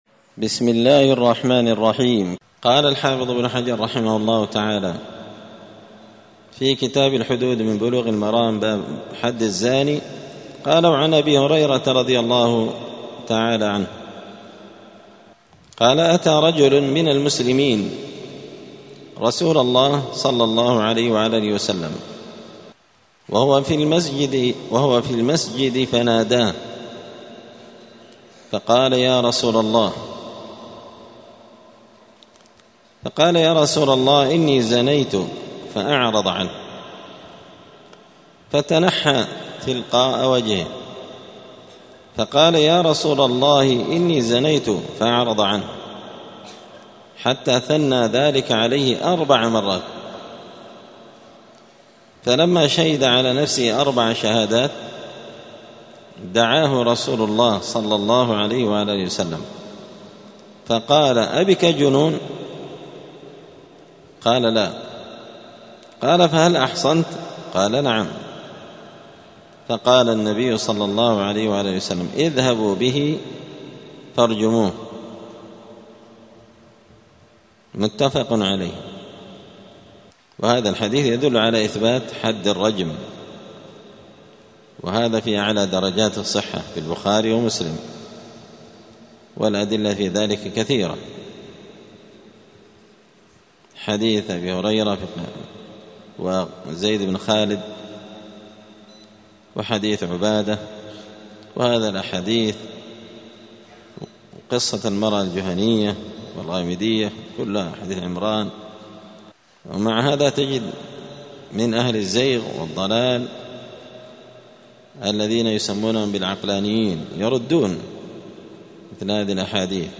*الدرس الرابع (4) {باب الإقرار المعتبر في الزنا}*